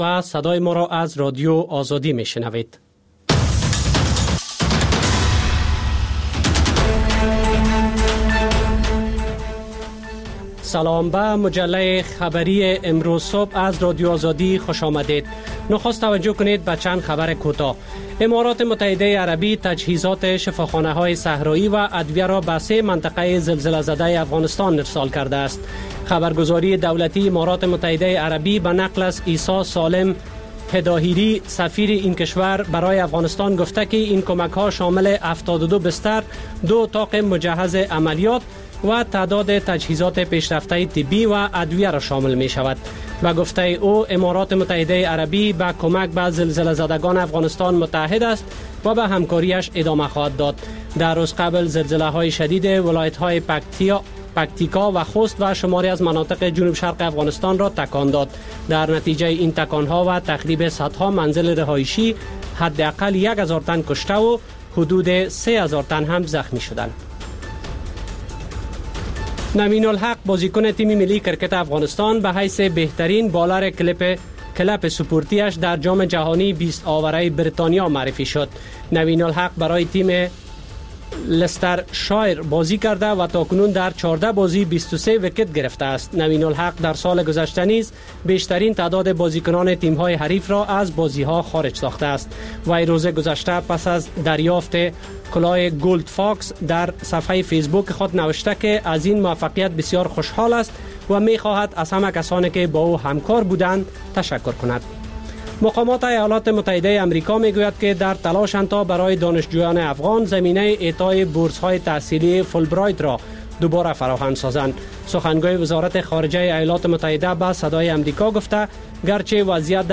پخش زنده - رادیو آزادی